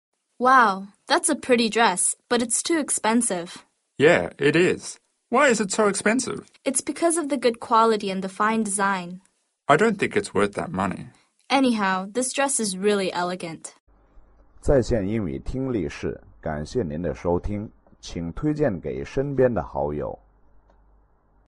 英语口语900句 01.03.对话.2.评价高级服装 听力文件下载—在线英语听力室